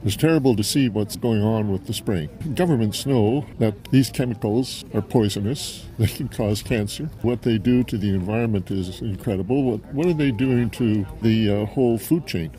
The Ministry of Natural Resources office on Riverside Drive in Pembroke was the site of a rally on Tuesday afternoon to stop the spray of herbicides, namely glyphosate, on Renfrew County forests.